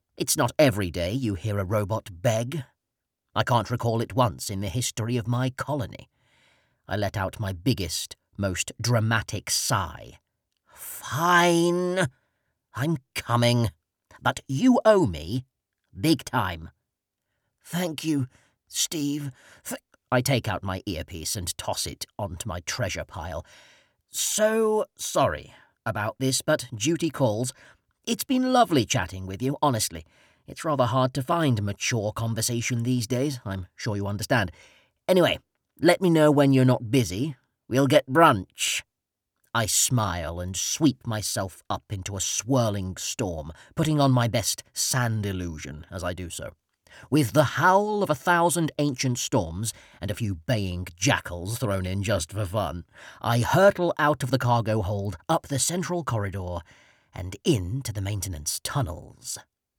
Narrator
Of Monsters and Mainframes (Earphones Winner - Sci Fi Comedy).mp3